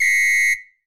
9LONGWHIS.wav